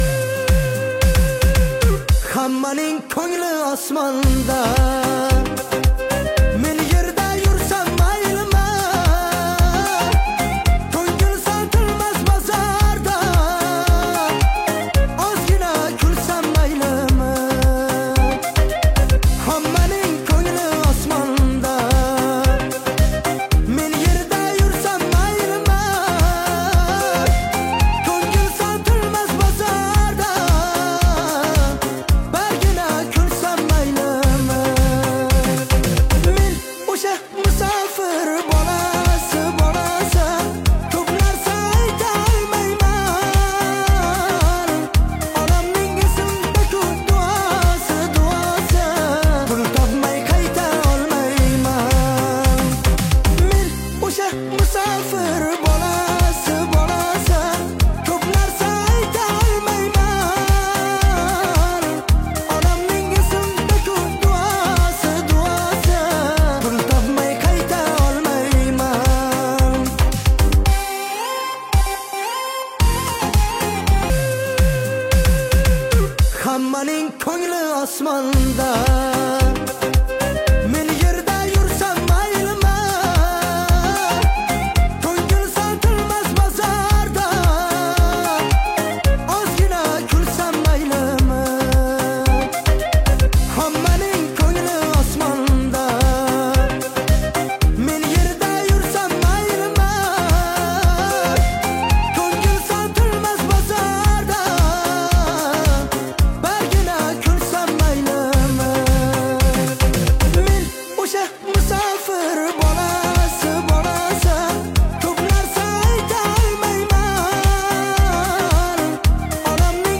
uzbek muzik, Узбекская музыка